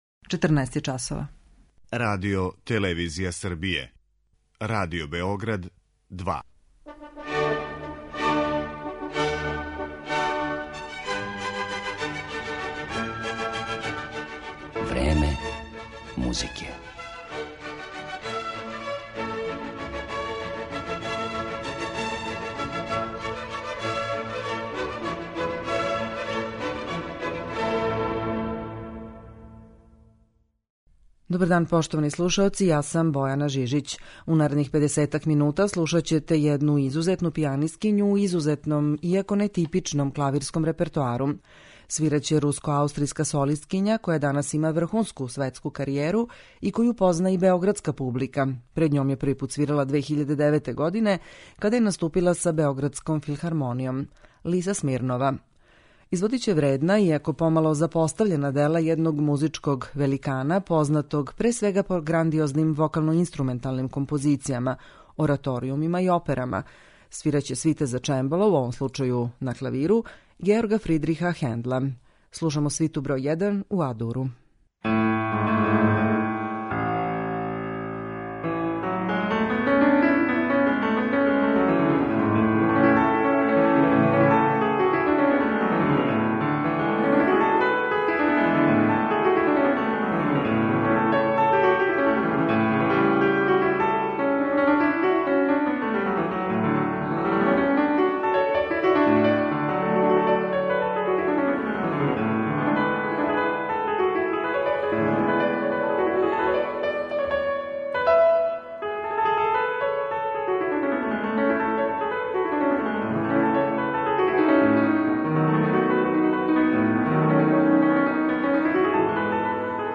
клавирских интерпретација свита за чембало